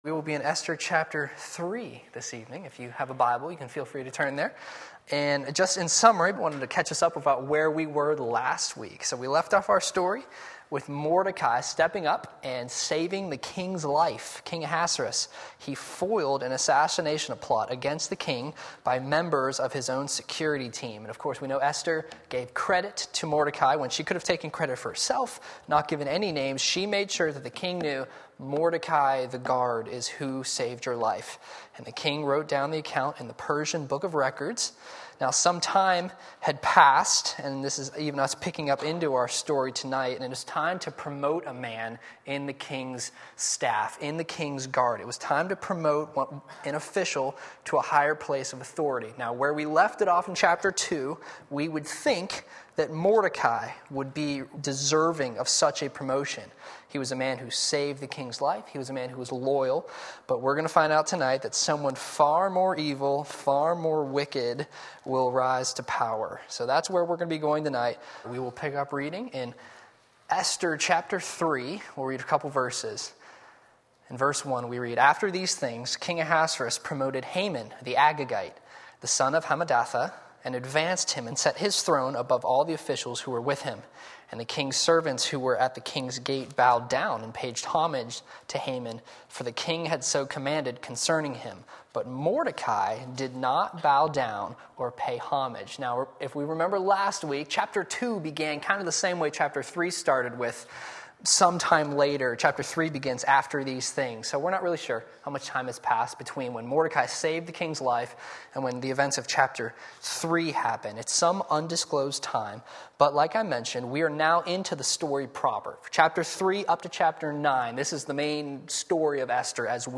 Sermon Link
Lesson 3 Esther 3 Wednesday Evening Service